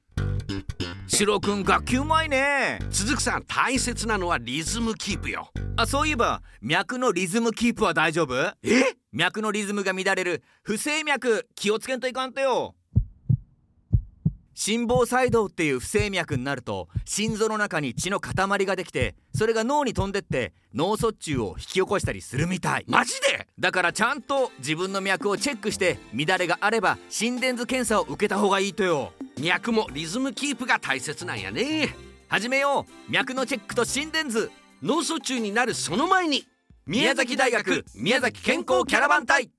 キャラバン隊ラジオCM放送中！【JOY FM 木曜日 朝8時台】